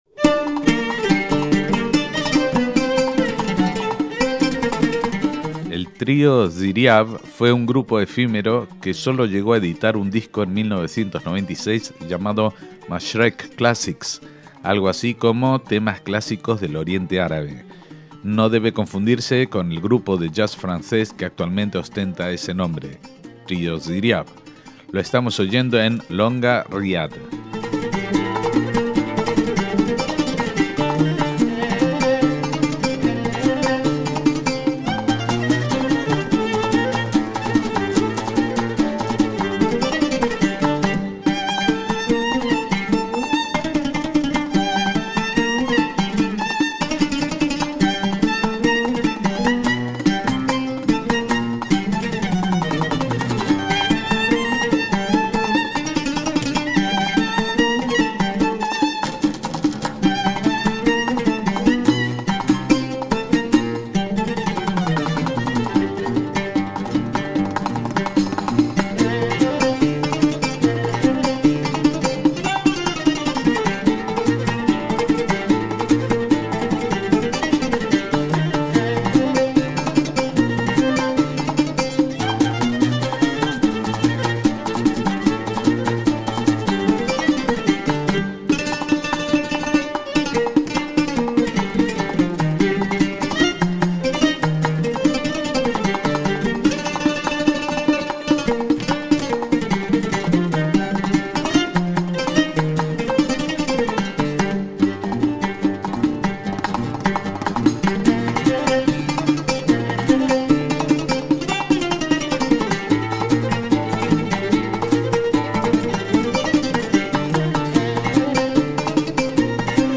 percusiones
violín
oud
música académica oriental